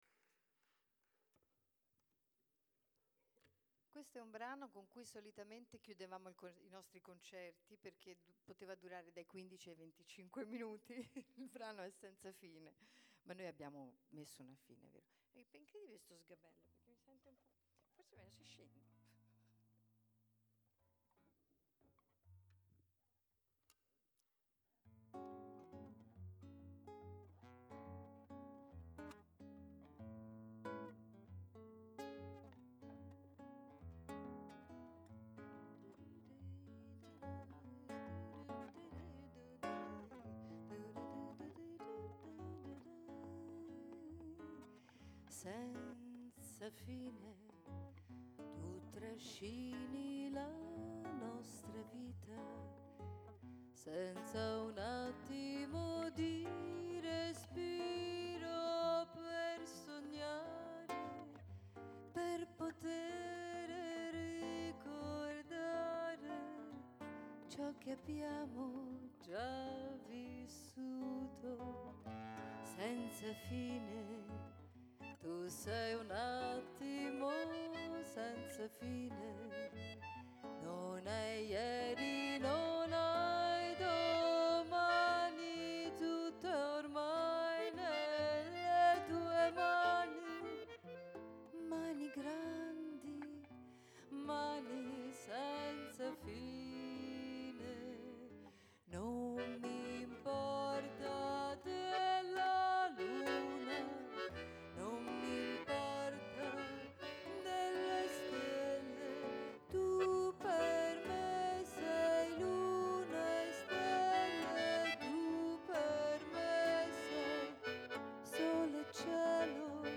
Alcuni brani live registrati in occasione del concerto
chitarra
fisarmonica